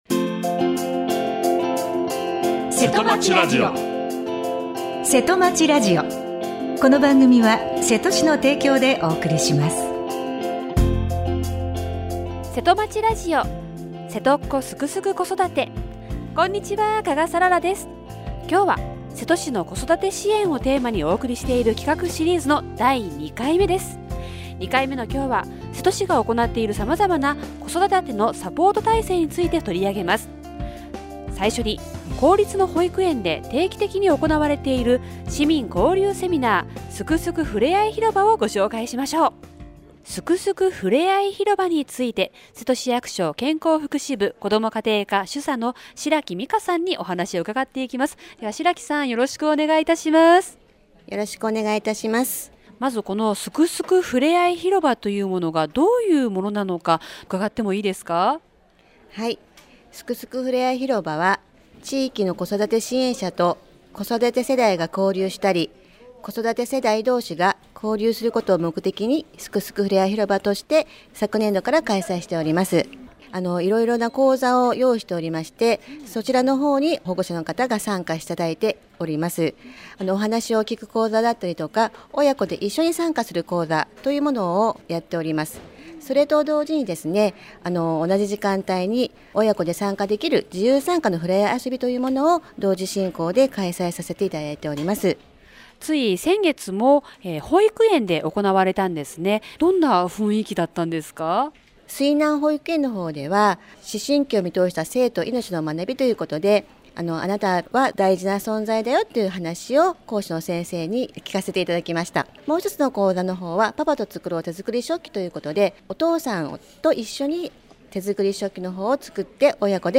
７月３１日に南保育園で行われた 「すくすくふれあい広場」に参加された皆さんの感想をお聞きしました。 また、「子育てサポーター養成講座」と「外国語通訳ボランティア」についても 担当者の方々にお話を伺いました。